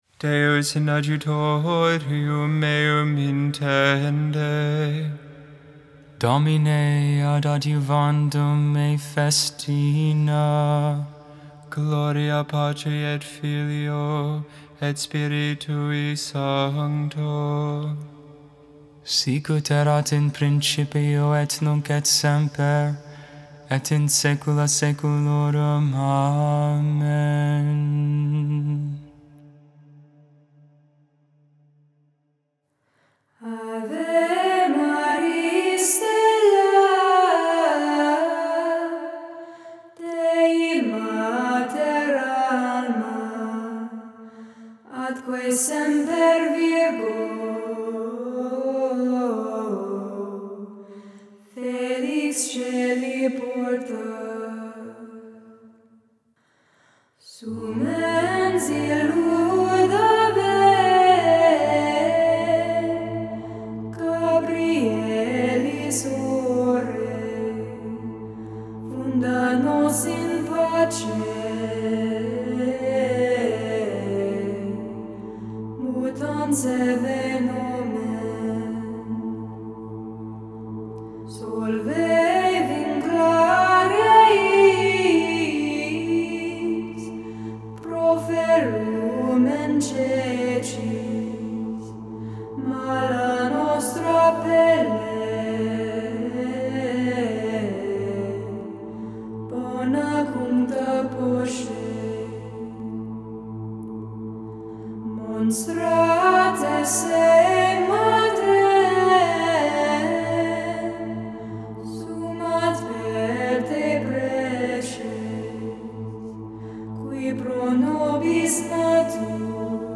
Ancient Marian Hymn
Magnificat: Luke 1v46-55 (English, Tone 8) Intercessions: Lord, fill our hearts with your love.